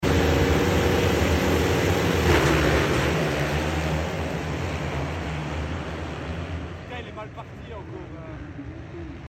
MXGP Of The Netherlands Start Sound Effects Free Download